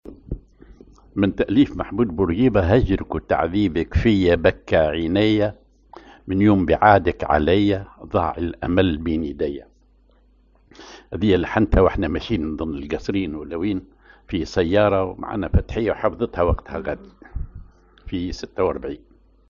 Maqam ar ماهور
genre أغنية